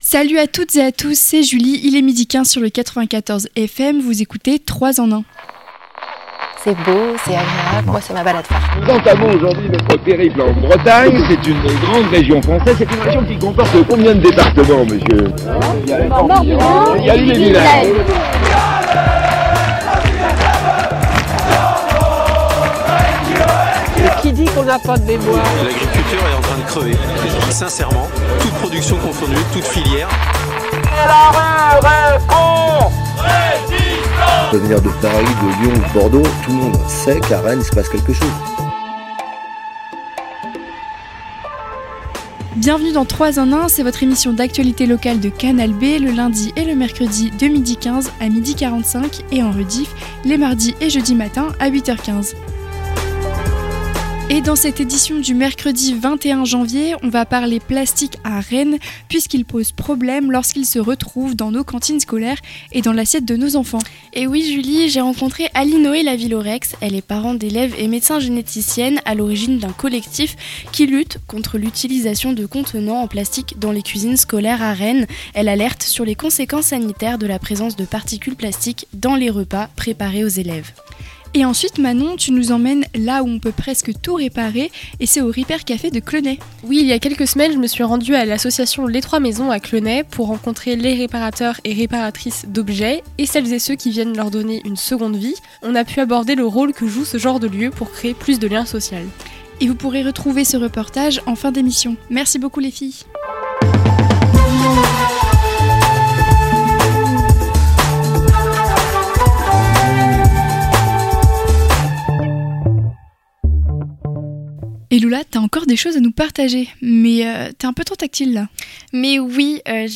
L'interview
Le Reportage